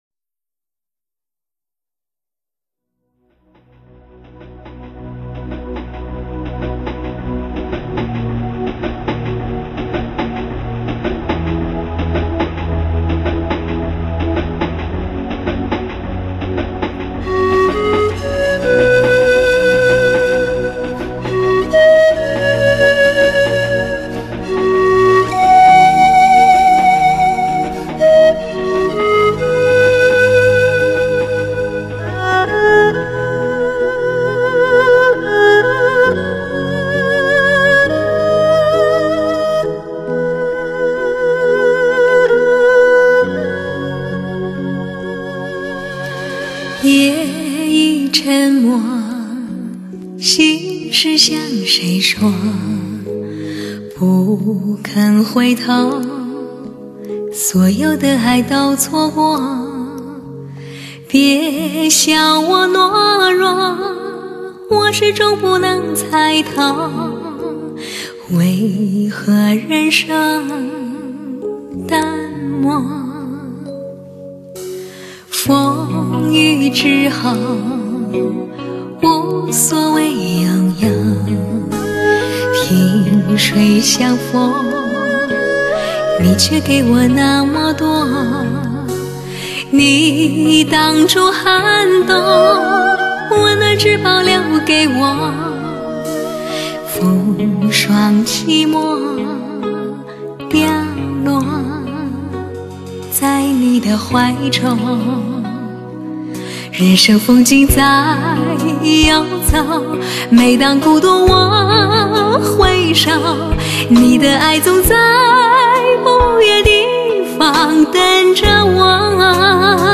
最贴心的HIFI女声。
韵味尽显一种诗化的声音，拨动心灵的弦。。